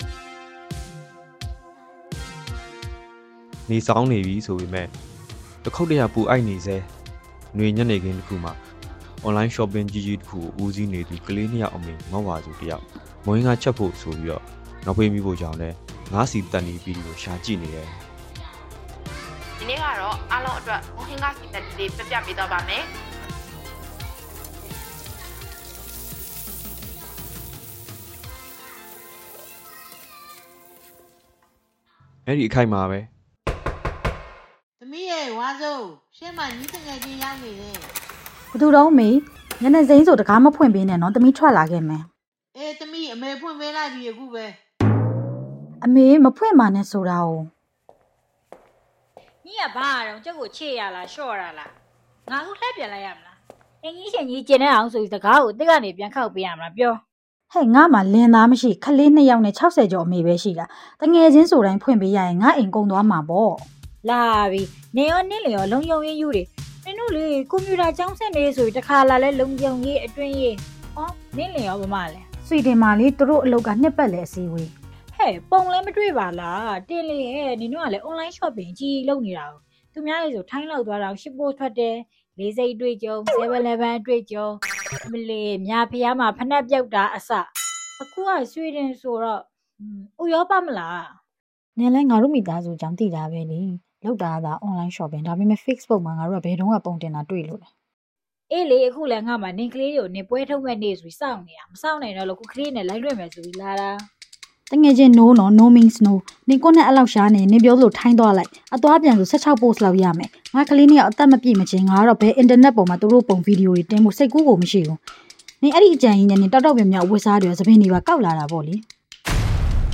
အခု အွန်လိုင်းအော်ပရာ ဆိုတဲ့ Radio အသံဇာတ်လမ်းက Social Media ပေါ်ငွေကြေးနဲ့ လူမှုသြဇာတည်ဆောက်တဲ့ ရေစီးကြောင်းမှာ အလုံးစုံ စီးမြောသူတွေနဲ့ ချင့်ချိန်သူတွေကြား အတွန်းအတိုက်ဖြစ်စဥ်တွေကို စိတ်ကူးပုံဖော်တင်ဆက်ထားခြင်းဖြစ်ပါတယ်။
နောက်ခံစကားပြောသူ BPN ဝိုင်းတော်သား တစ်ဦး